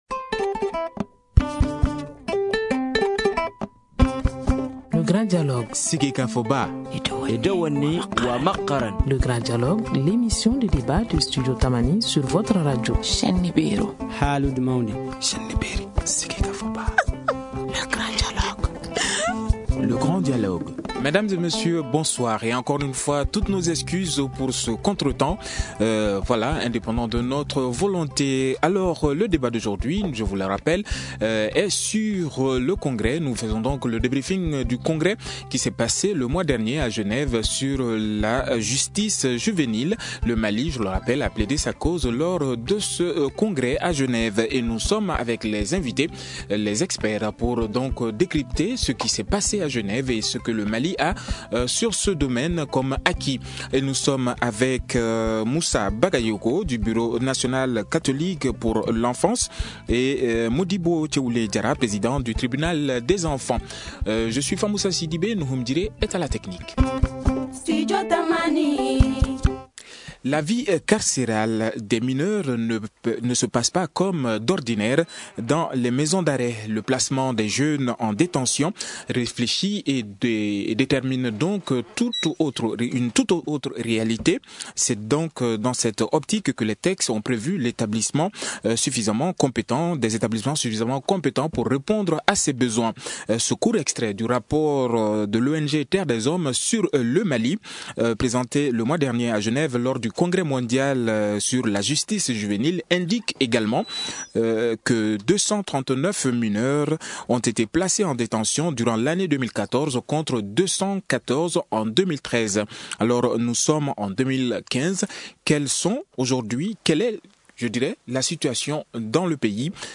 Trois invités